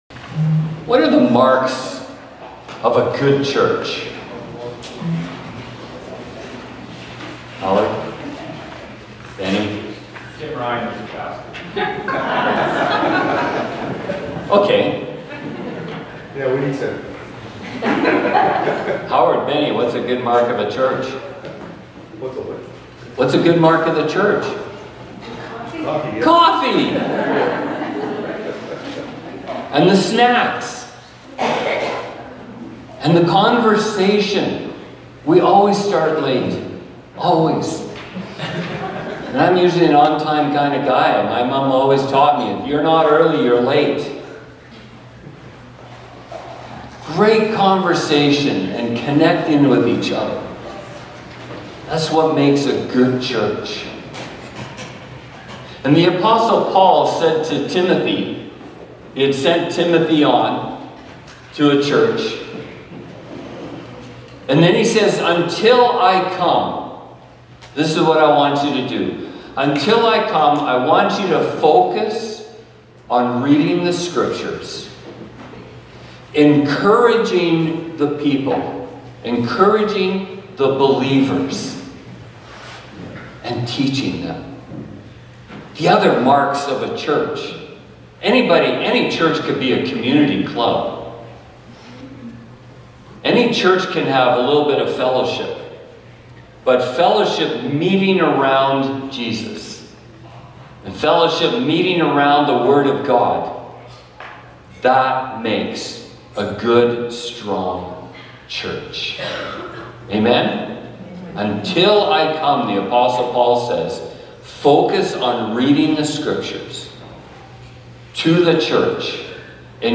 Sermons | Rosenort Community Church